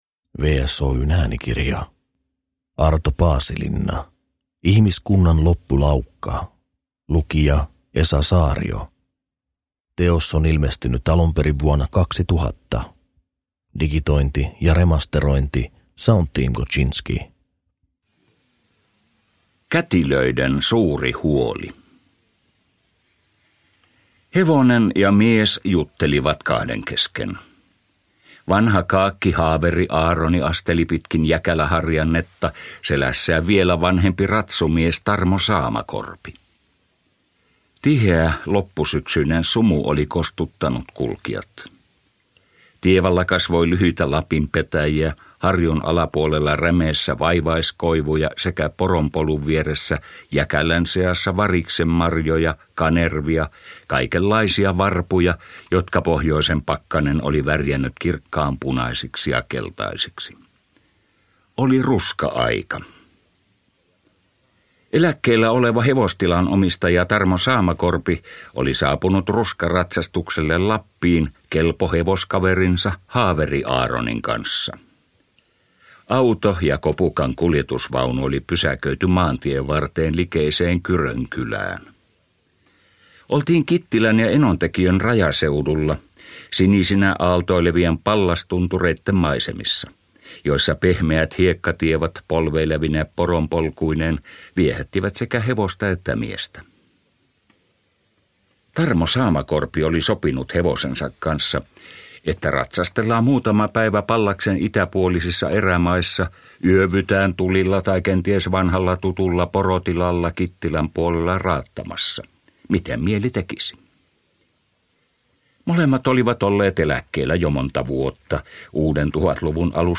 Äänikirja on ilmestynyt ensimmäisen kerran vuonna 2000.